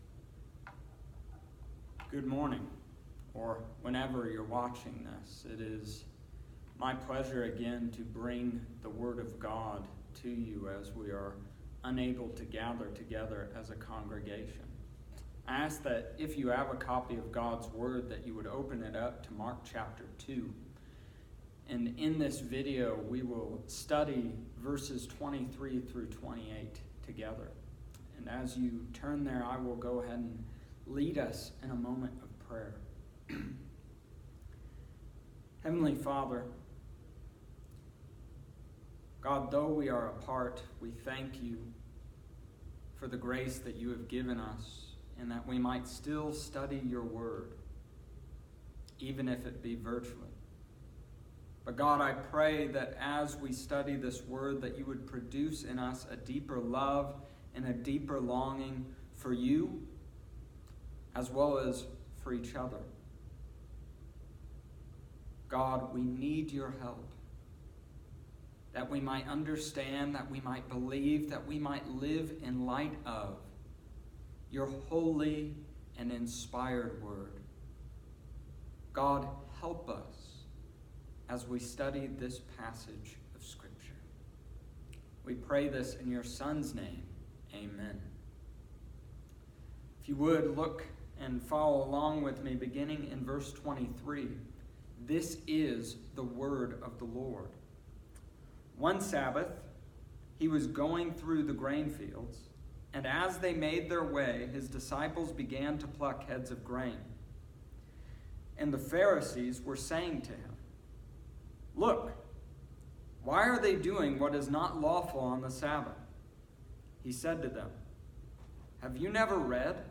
Here is the Bible Study I recorded for this morning.